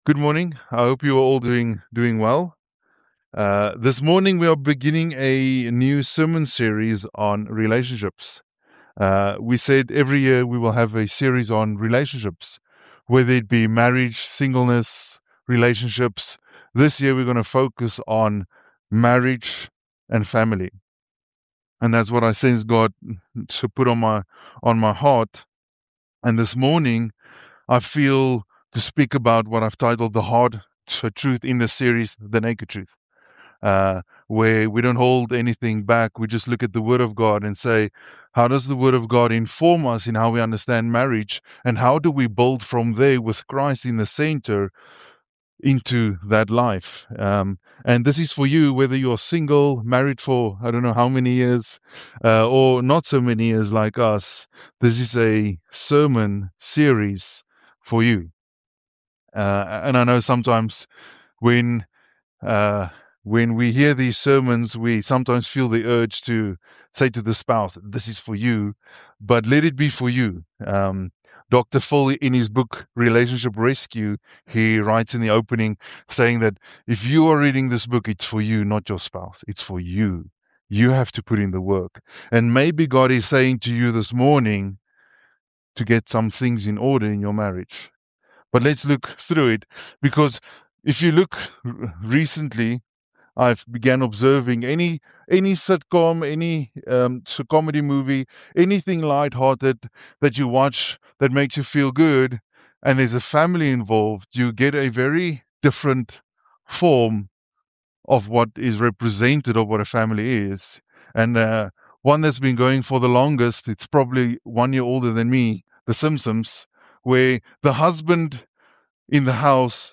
GBC Podcast to share audio sermons and talks.